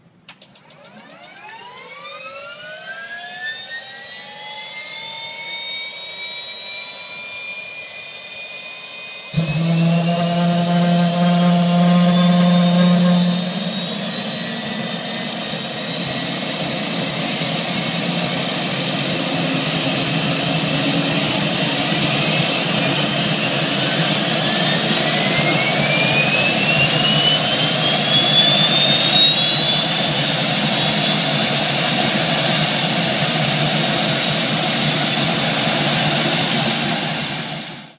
starter_start.wav